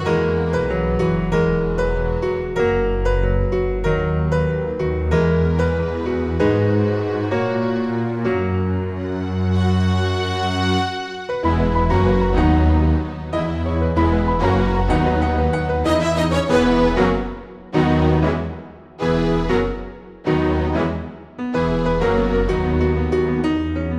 Original Female Key